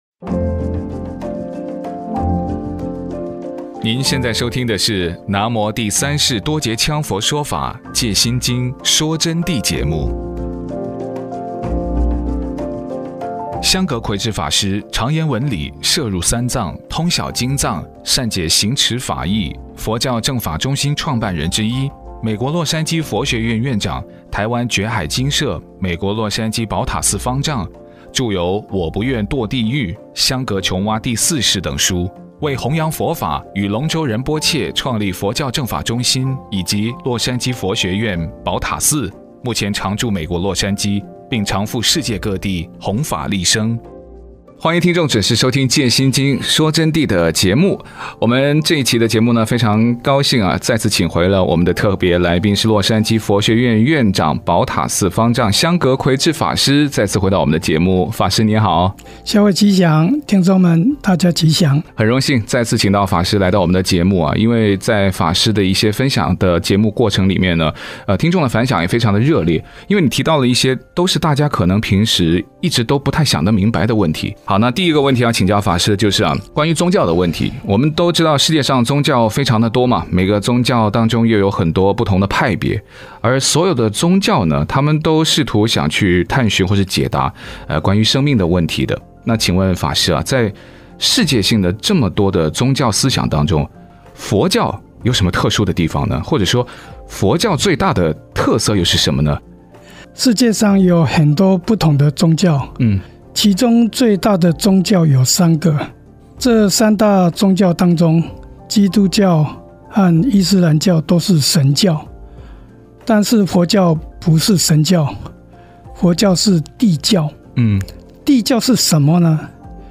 佛弟子访谈（二十三）为什么佛教是圣教？